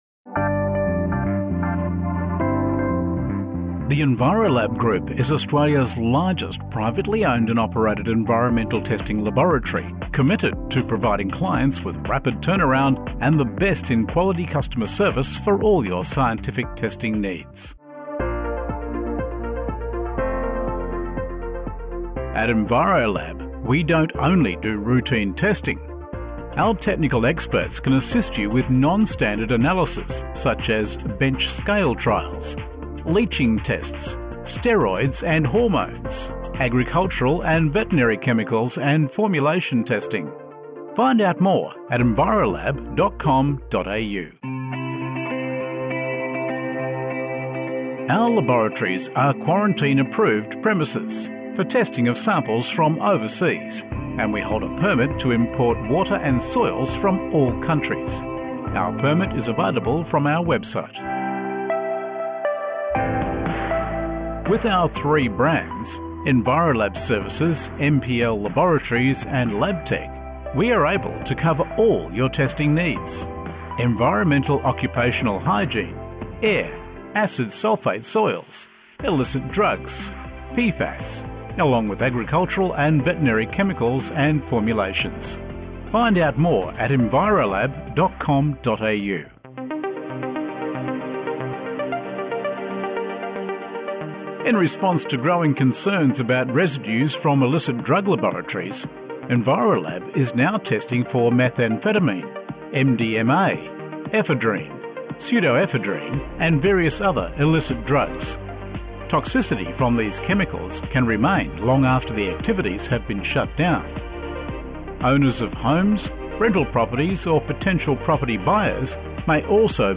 A better customer experience – On Hold